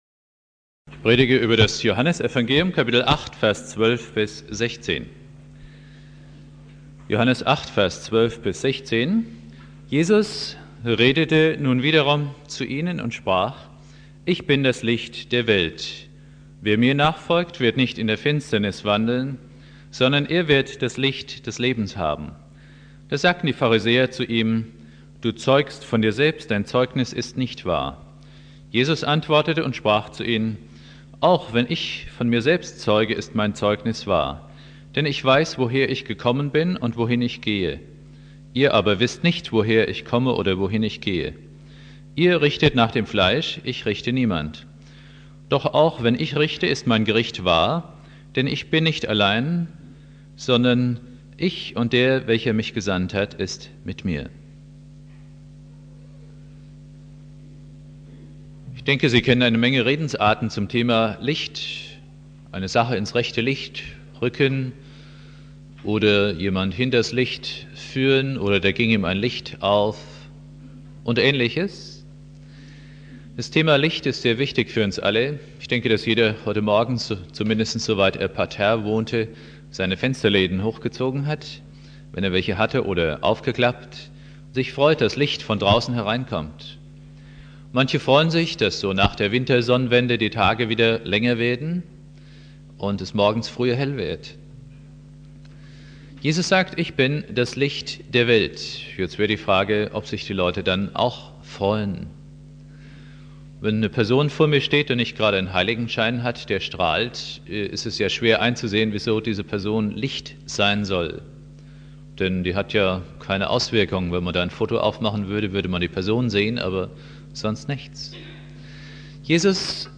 Predigt
2.Weihnachtstag